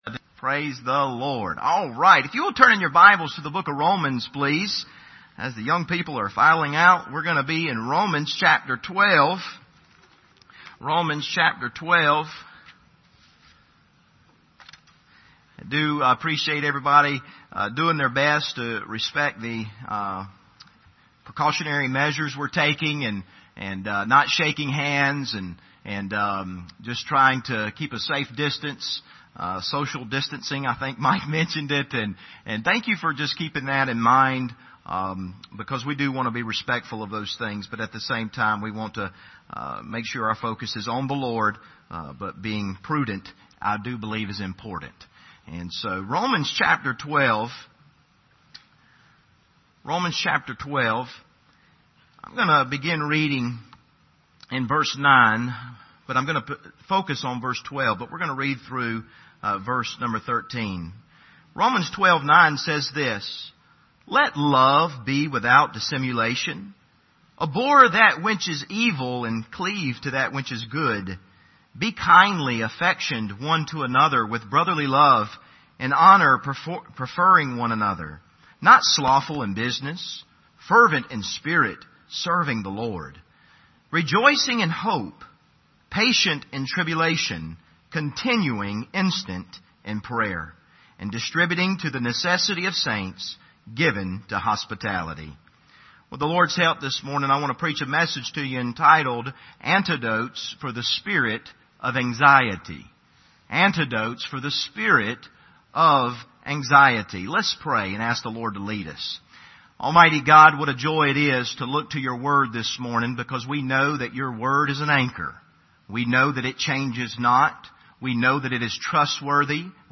Preacher
Service Type: Sunday Morning